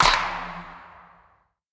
Steel Clap.wav